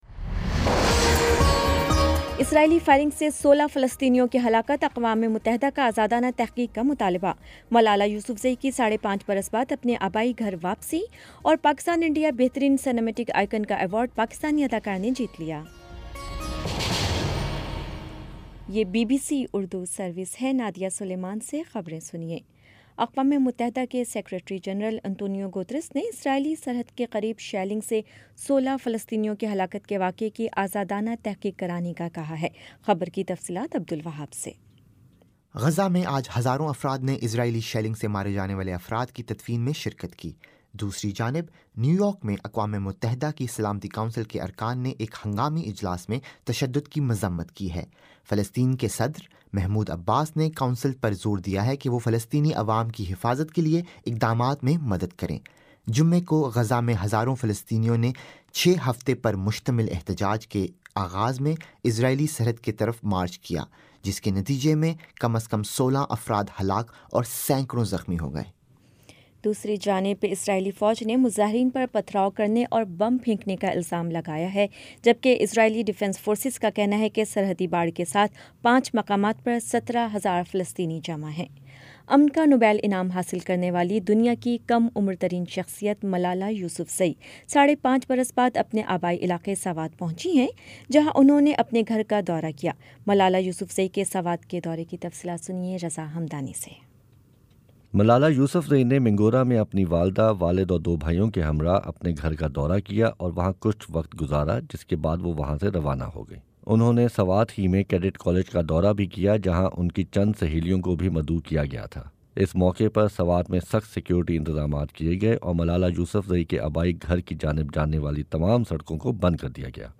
مارچ 31 : شام پانچ بجے کا نیوز بُلیٹن